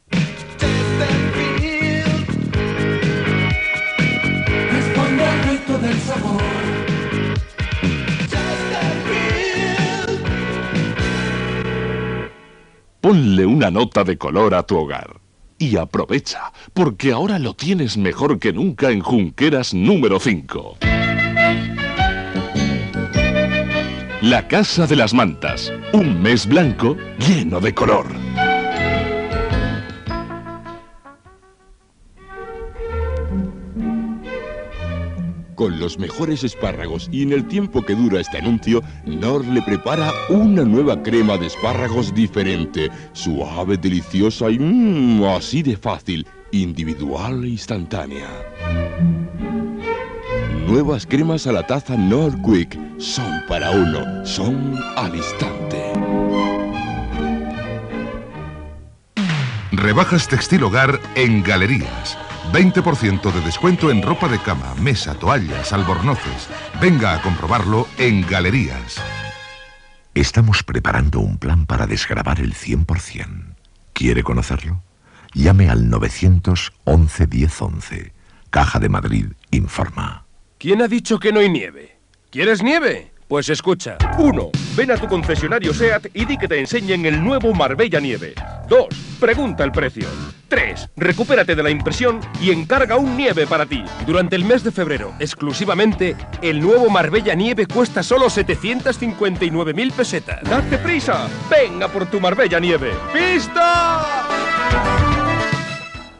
Bloc publicitari